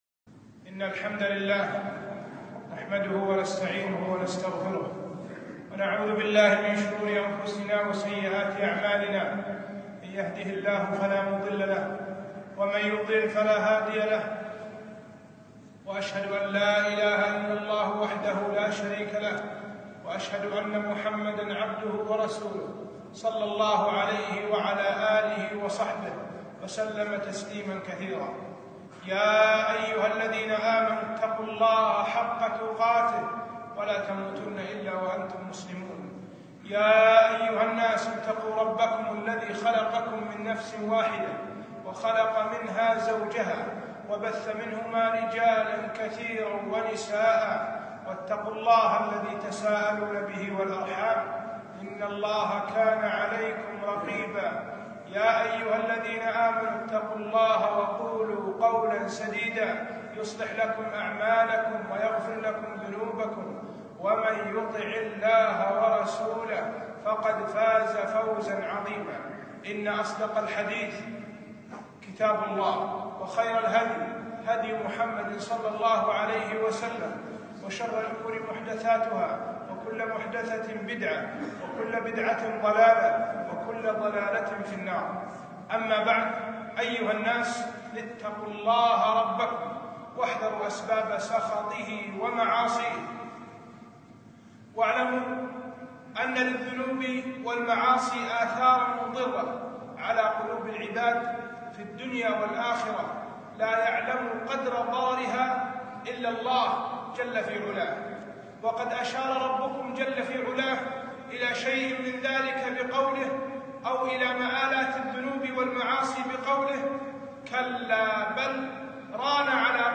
خطبة - أثر المعاصى على القلوب